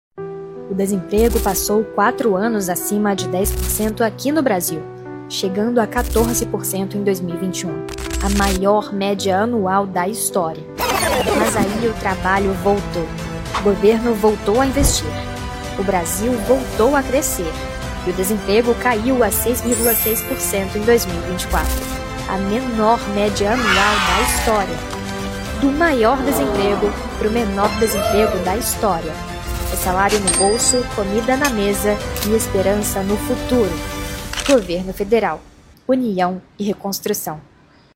SPOT | Do maior desemprego pro menor desemprego da história!